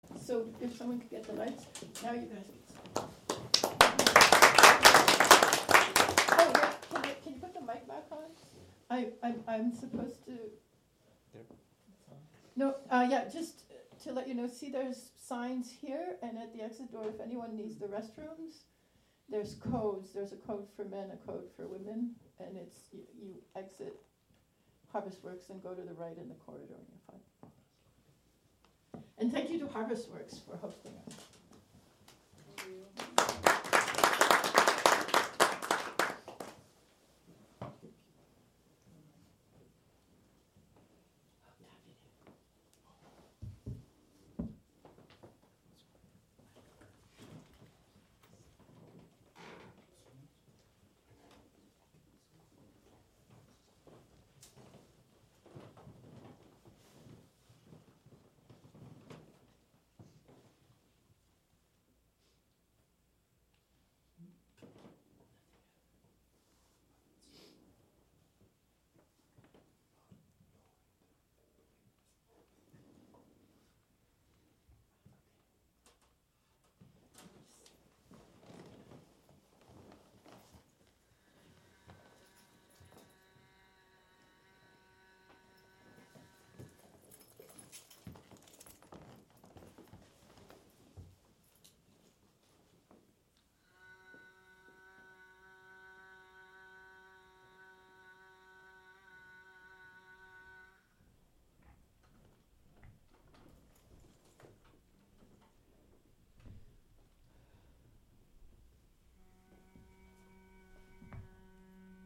Live from Experimental Intermedia